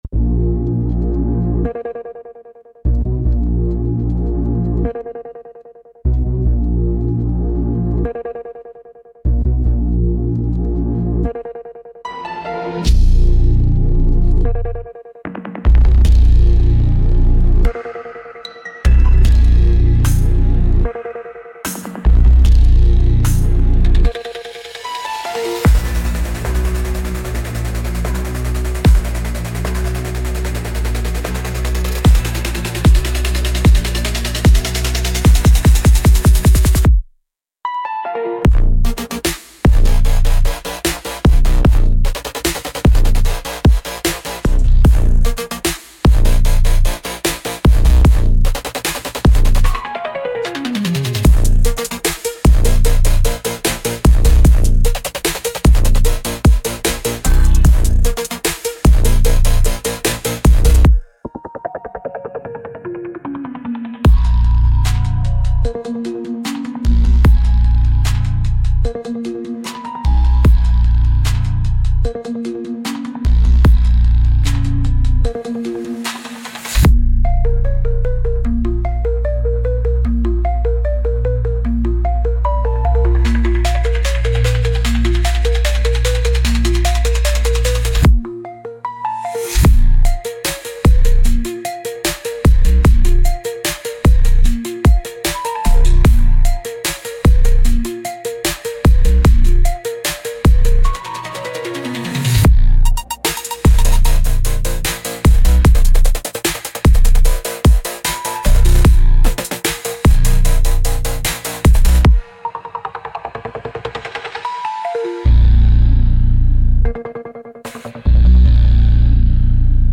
Instrumental - Code of the Swampwalker